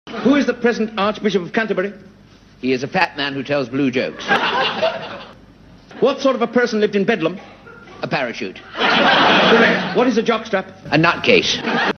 Click on the audio symbol against each picture to hear some music from the era in which the photo was taken.